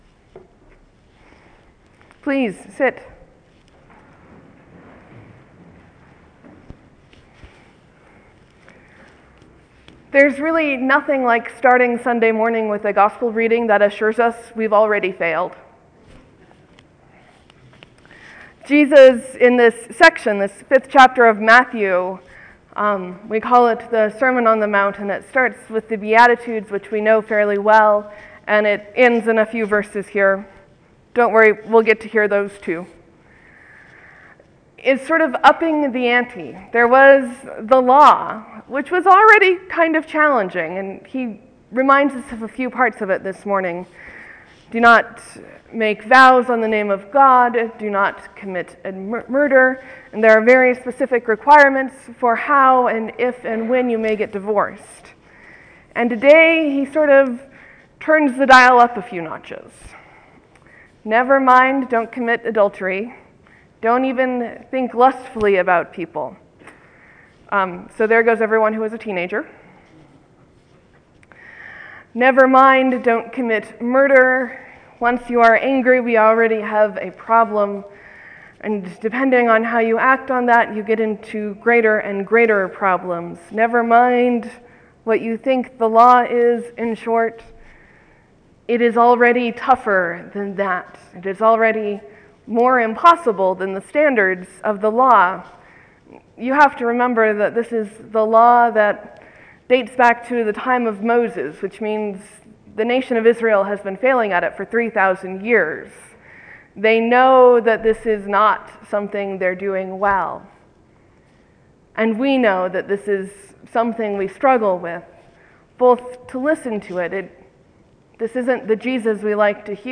Different not Dangerous, sermon for Epiphany 6 16 Feb 2014